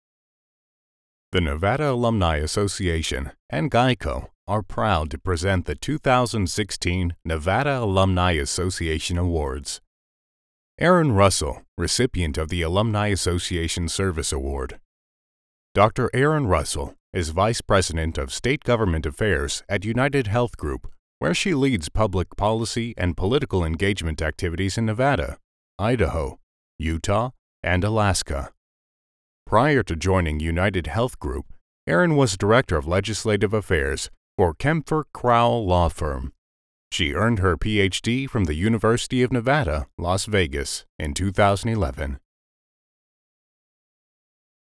mid-atlantic
middle west
Sprechprobe: Sonstiges (Muttersprache):
Trustworthy, Attention Grabbing, Believable, Authoritative, Sincere, Powerful, Friendly, Warm, Energetic, and Relatable are also proper adjectives.